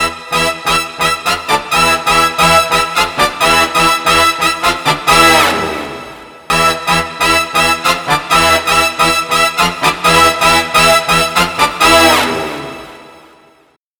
Но как звуковое сырьё для духовой меди звуки Montage и MODX по сравнению с Motif по-моему ощутимо выросли.